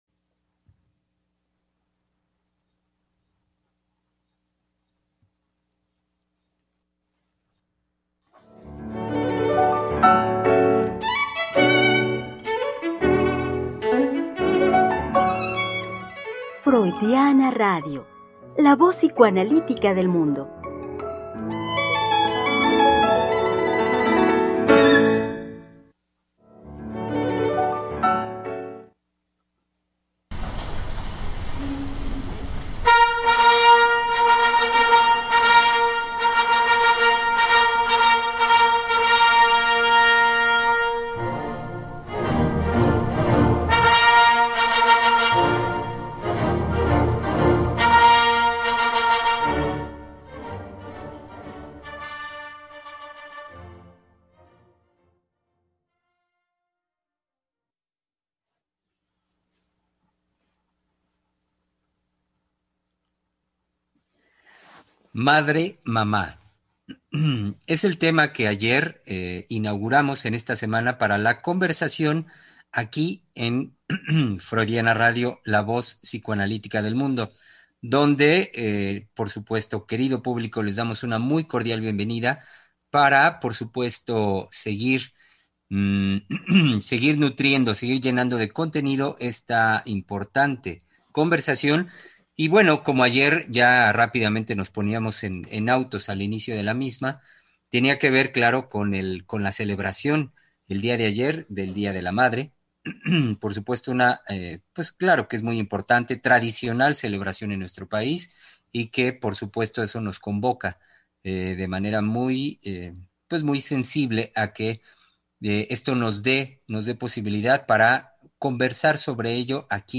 Martes de Palabra de Hombre por Freudiana radio conversaremos sobre “Madre… mamá” con los psicoanalistas